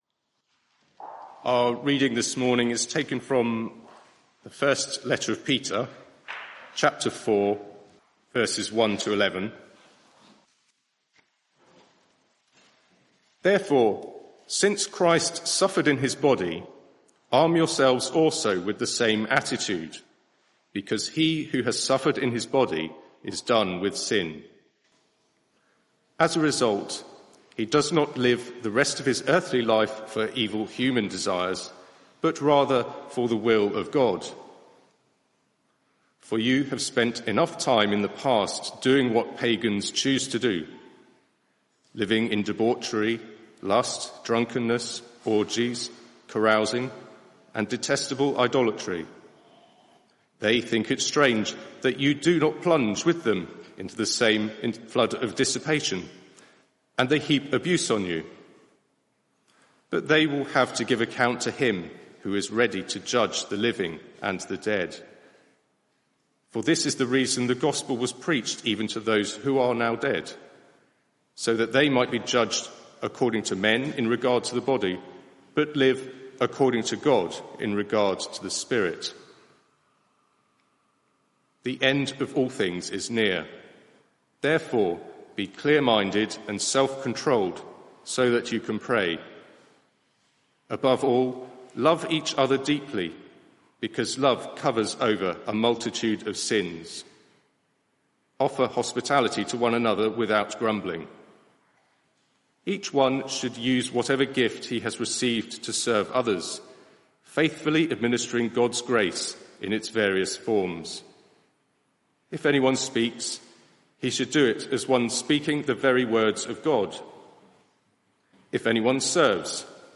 Media for 11am Service on Sun 25th Jun 2023 11:00 Speaker
Sermon (audio)